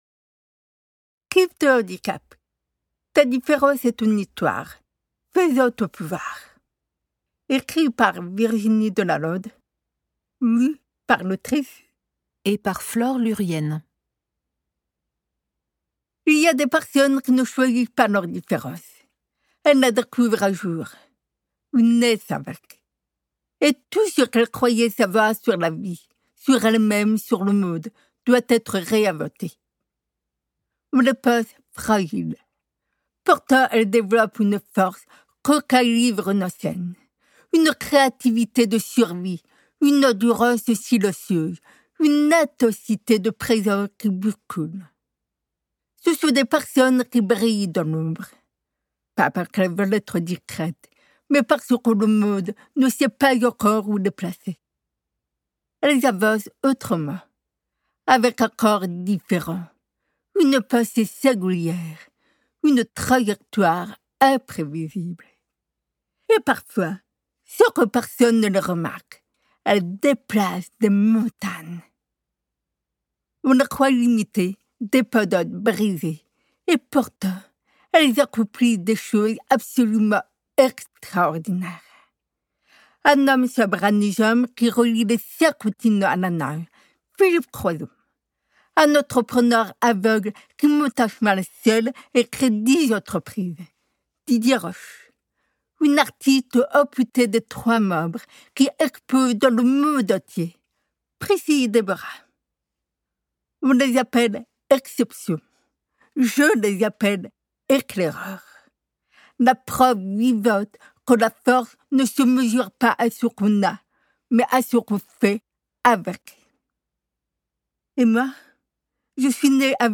Audiolib Date de publication : 2025-08-27 Ce livre ne parle pas de limites. Il parle de possibilités.
Interprétation humaine Durée : 06H15 21 , 95 € Ce livre est accessible aux handicaps Voir les informations d'accessibilité